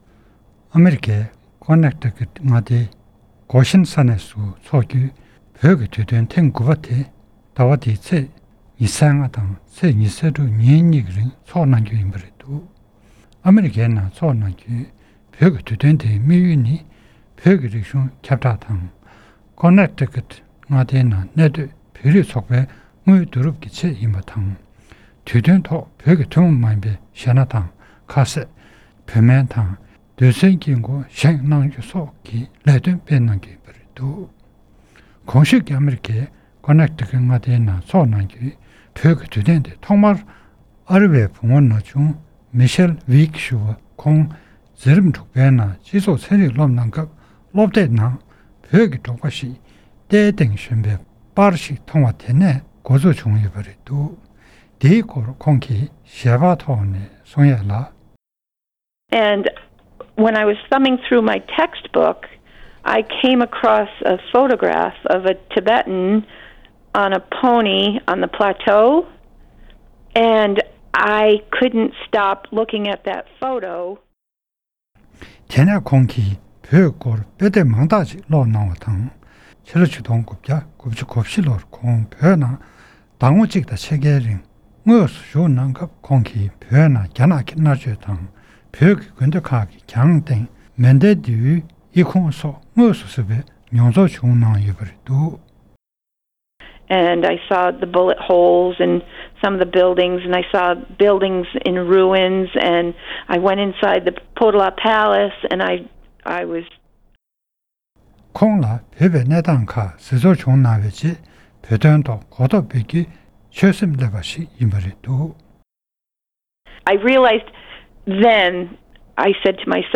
སྒྲ་ལྡན་གསར་འགྱུར། སྒྲ་ཕབ་ལེན།